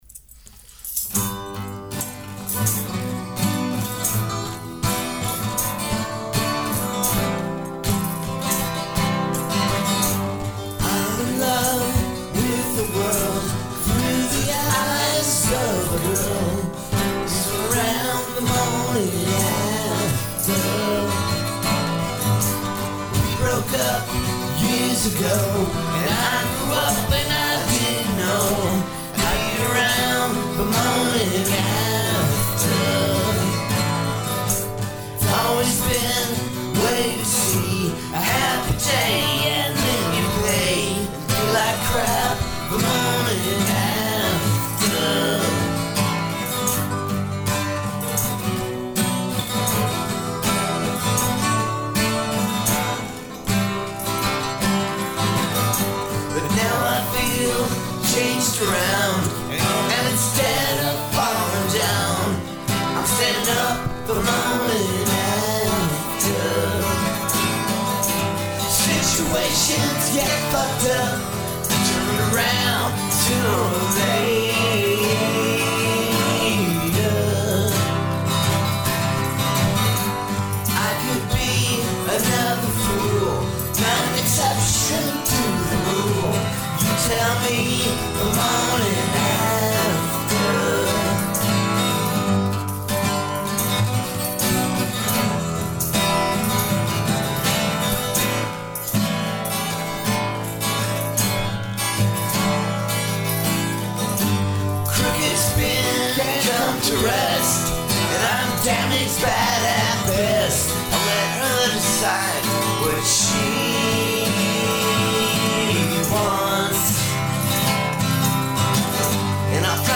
A song for people with brittle emotions.